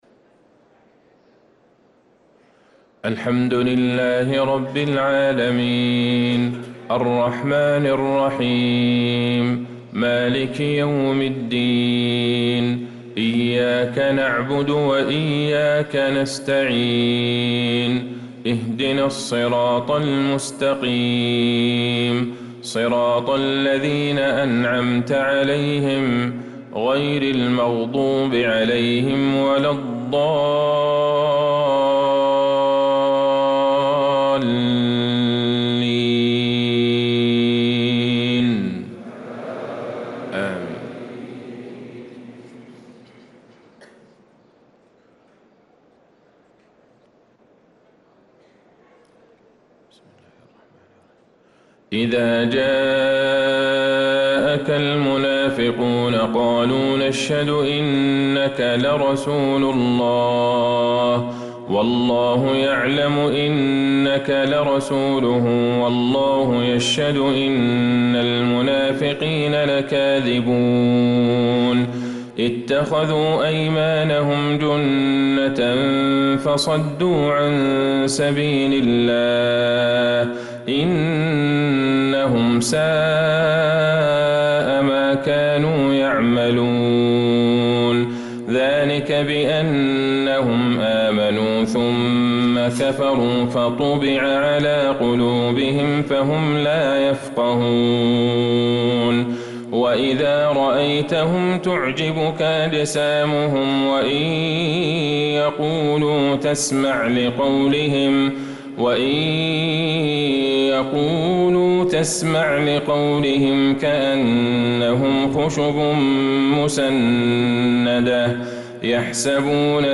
صلاة الفجر للقارئ عبدالله البعيجان 21 شوال 1445 هـ
تِلَاوَات الْحَرَمَيْن .